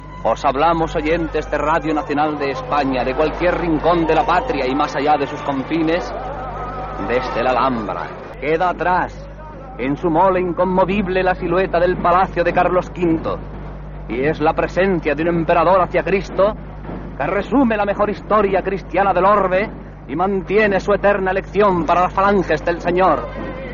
Transmissió de la Setmana Santa de Granada